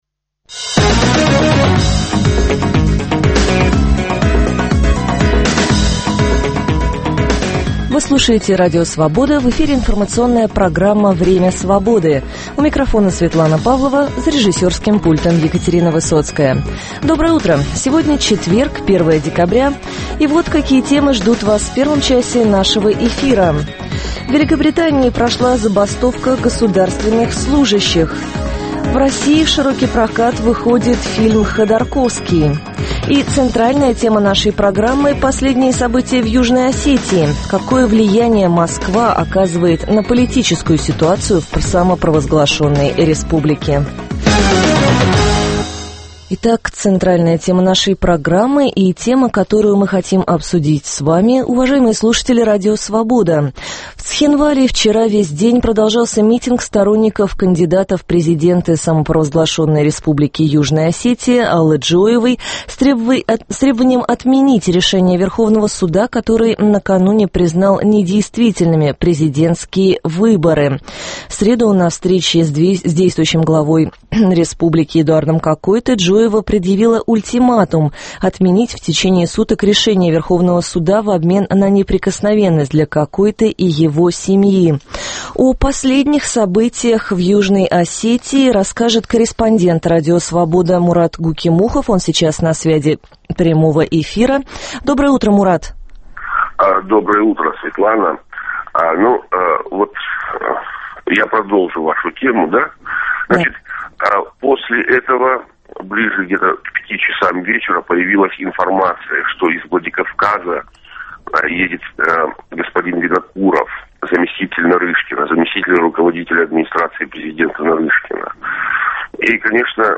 Перспективы и подробности первых событий дня наступившего, дискуссии с экспертами на актуальные темы, обсуждение вопроса дня со слушателями в прямом эфире.